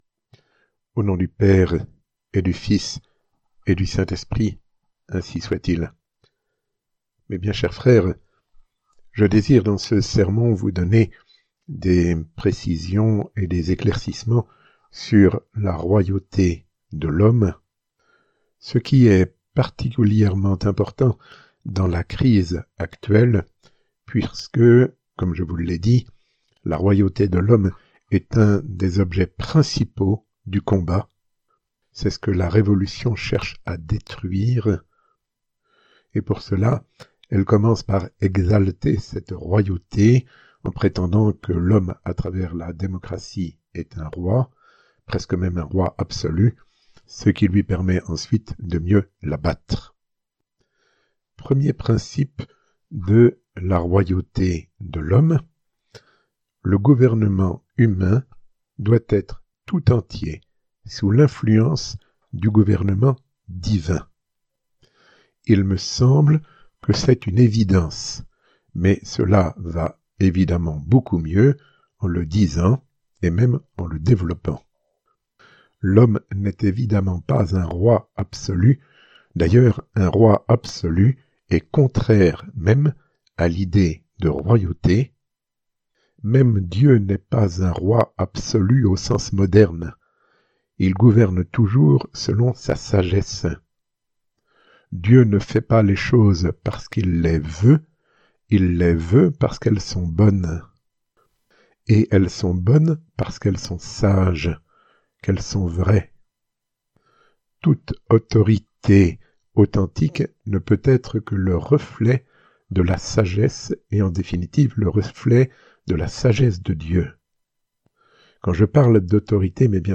Résumé du sermon